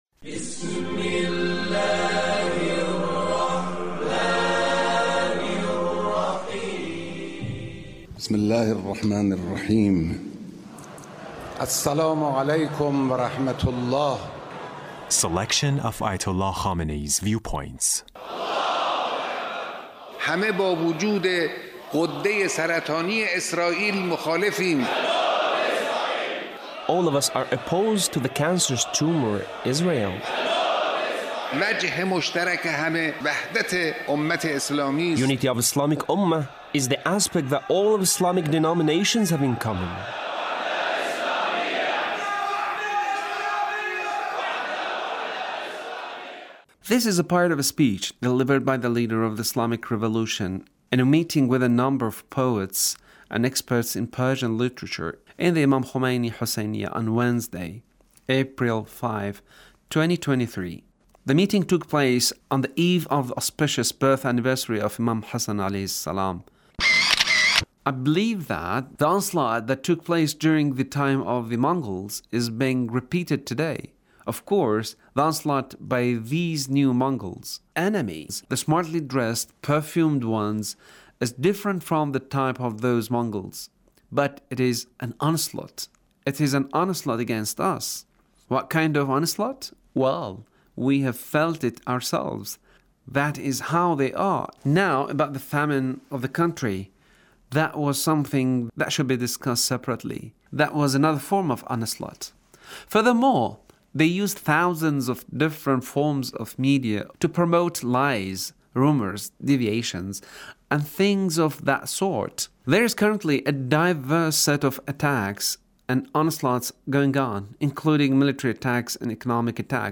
Leader's Speech on Poet's Gathering